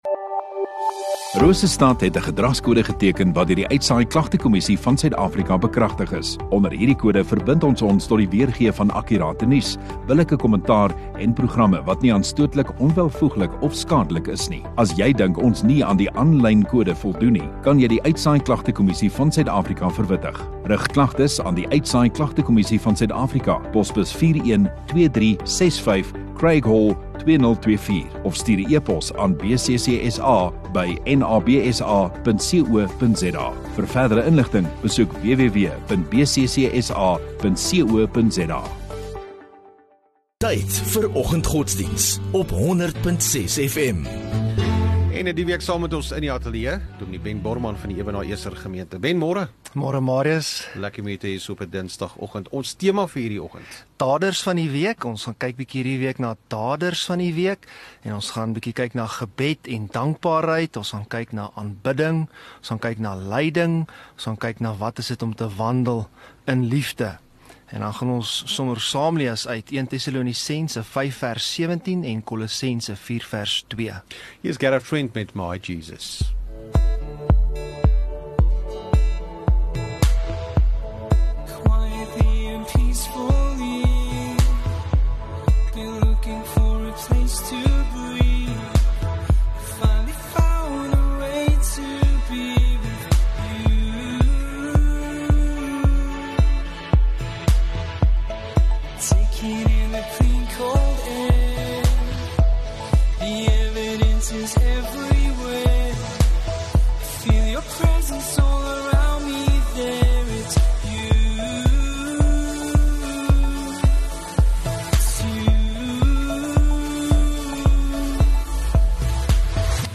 14 Jan Dinsdag Oggenddiens